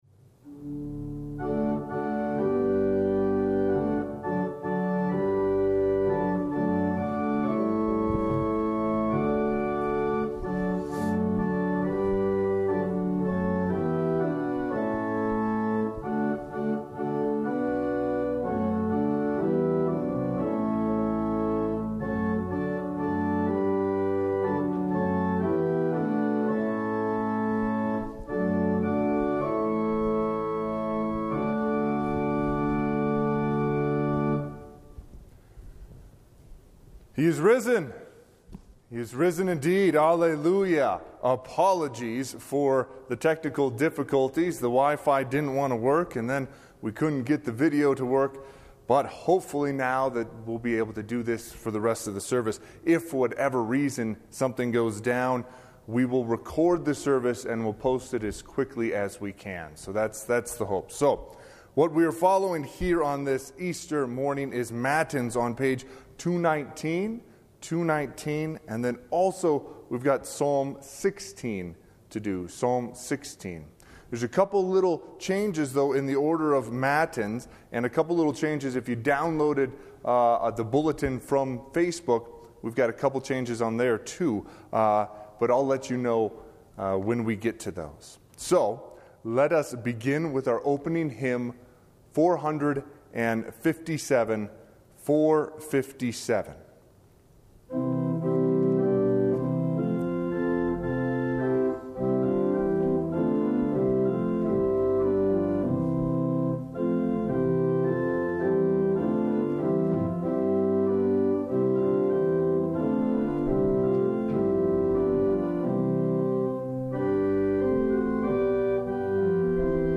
Easter Sunday Matins